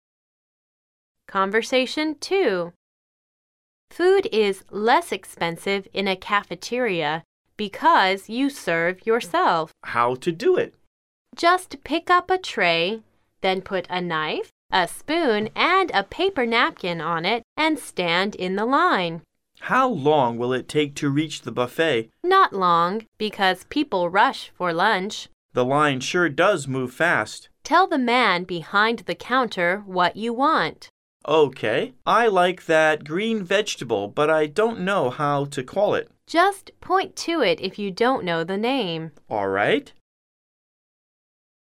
Conversation 2